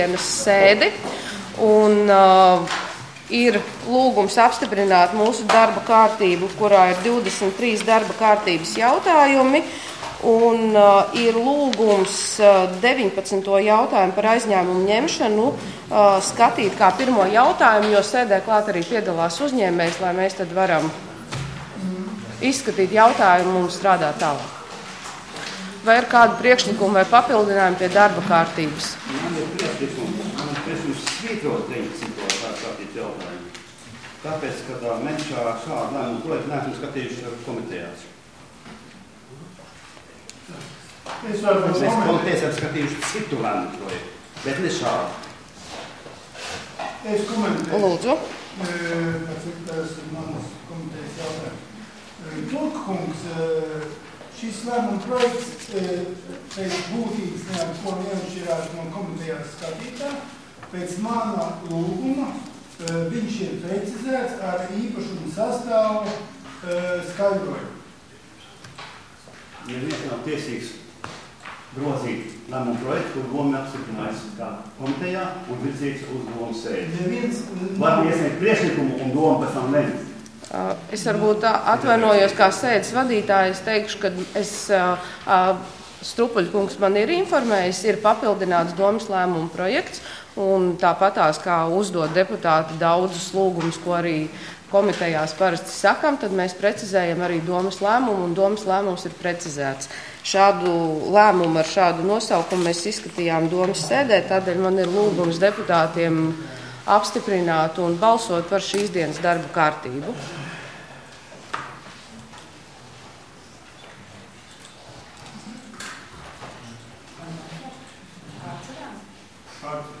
Domes sēde Nr. 16